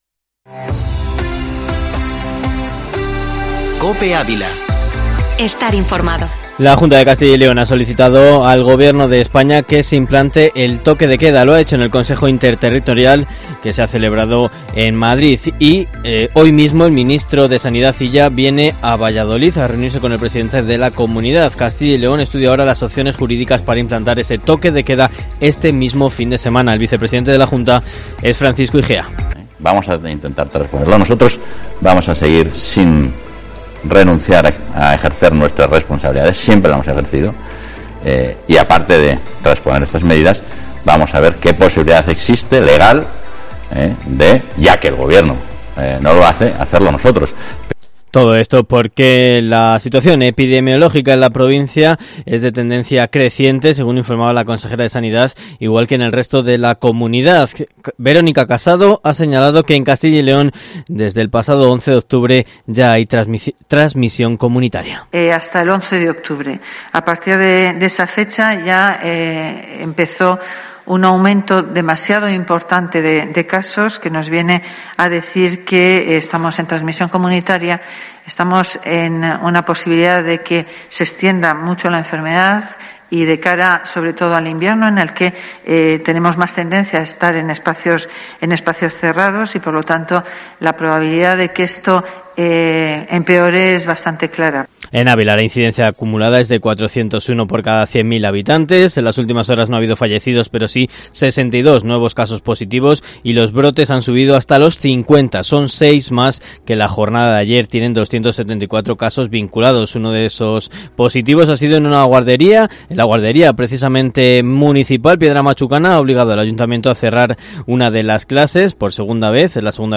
Informativo matinal Herrera en COPE Ávila 23/10/2020